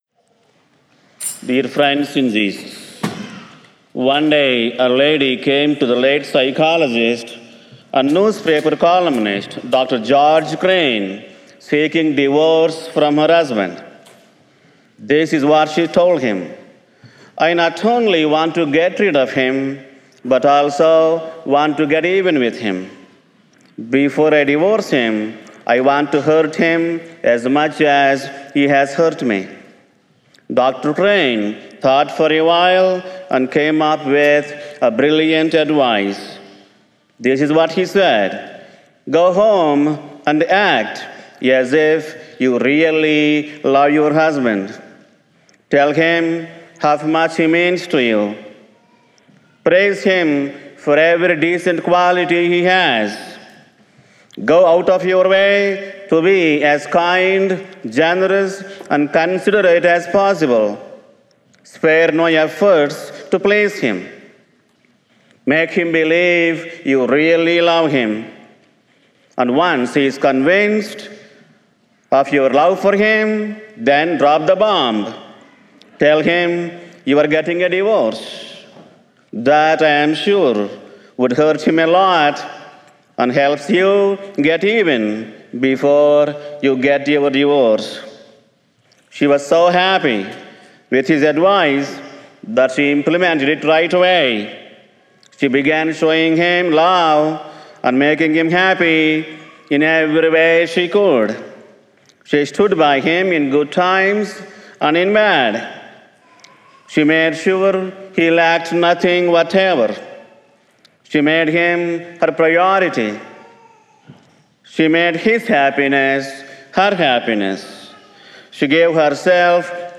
He offered this message during Sunday morning Mass at Ascension Catholic Church in Overland Park, KS on December 5, 2021.